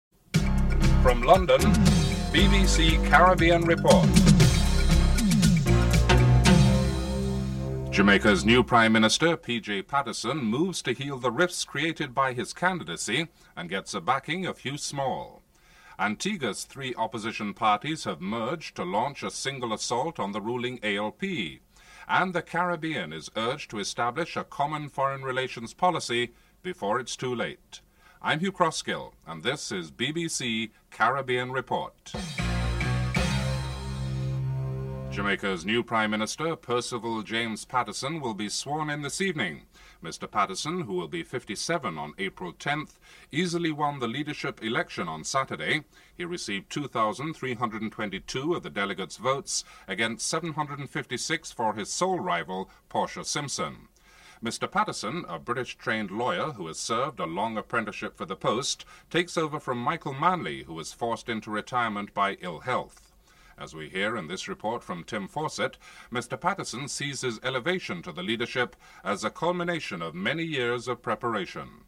1. Headlines (00:00-00:33)
Finance Minister Hugh Small expresses his readiness to work with Mr. Patterson in an interview with the anchor (03:32-06:14)